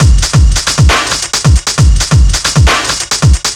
Power Break 2 135.wav